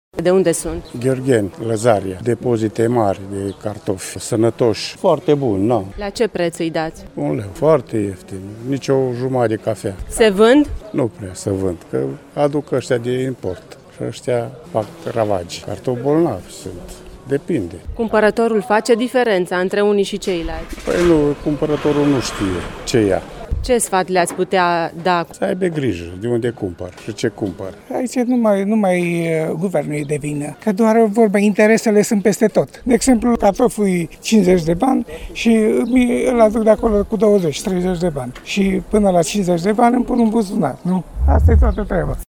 Comercianții din piețe care au pe tarabe cartofi de Harghita au sesizat și ei că au scăzut vânzările: